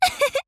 Penny's voice from the official Japanese site for WarioWare: Move It!
WWMI_JP_Site_Penny_Voice.wav